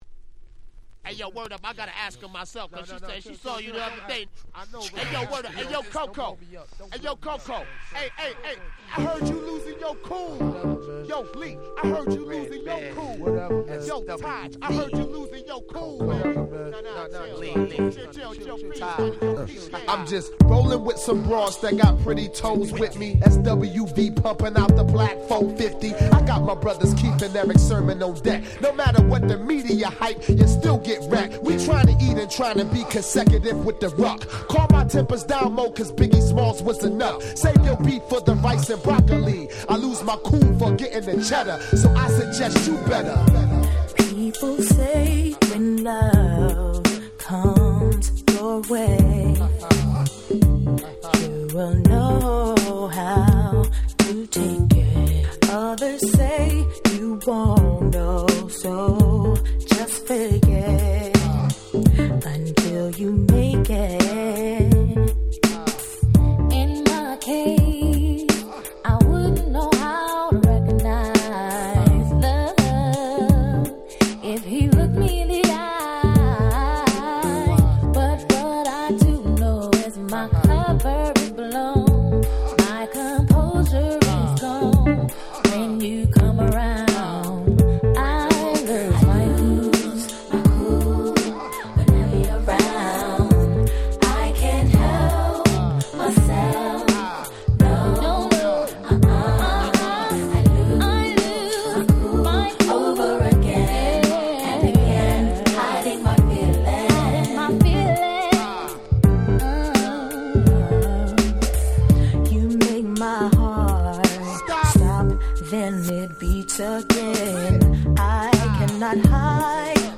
97' Nice R&B / Hip Hop Soul !!
派手さは無いですがすごく艶っぽいです。